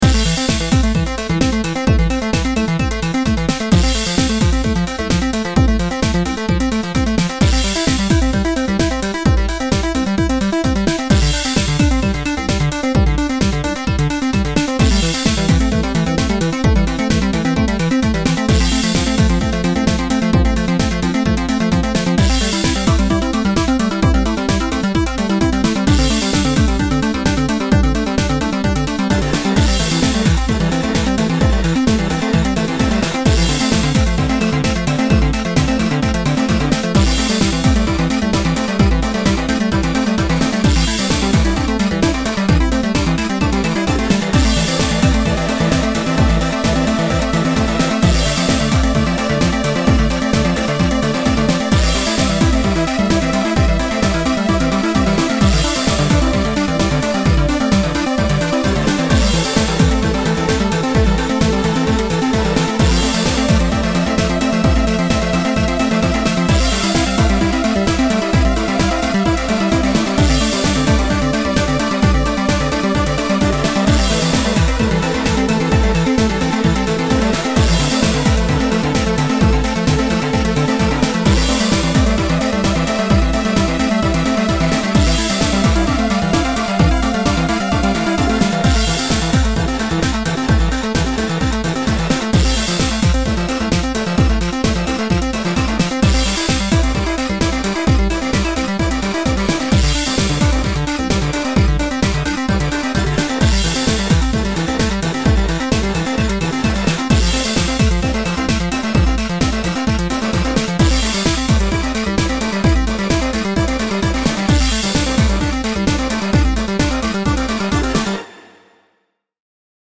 Soundtrack for a dance scene in a trashy Euro bar, where two soon-to-be lovers go out for the first time together and have so much fun, for a film that will never be made